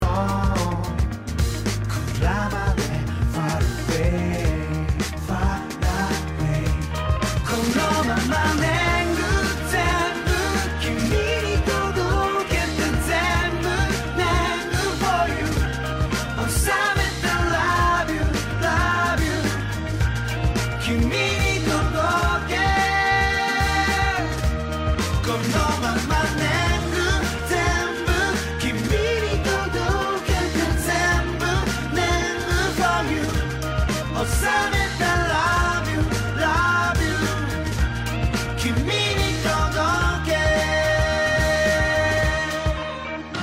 洋楽アプローチな本格サウンドに、日本史ネタの歌詞を乗せて遊び倒す、抱腹絶倒の作品です!!
Tag       Japan R&B